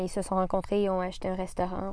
•  Au Québec, le pronom ils suivi d’un verbe n’entraîne pratiquement jamais une liaison, alors que celle-ci est considérée comme catégorique.